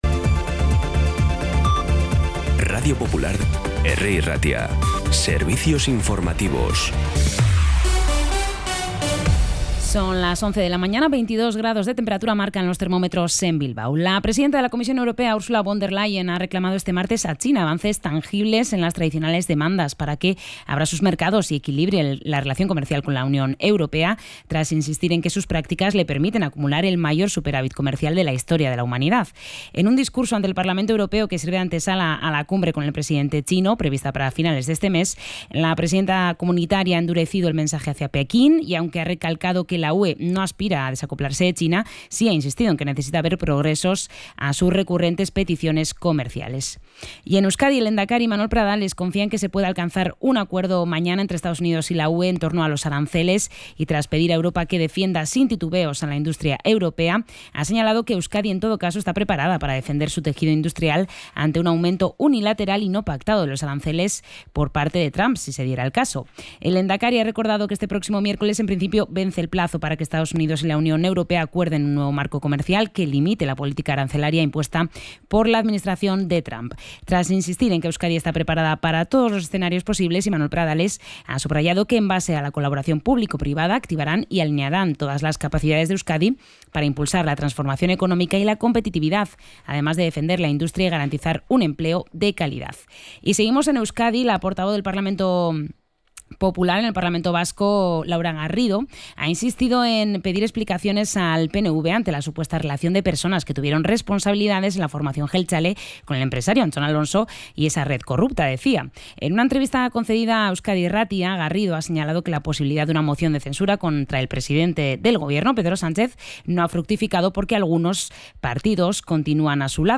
Información y actualidad desde las 11 h de la mañana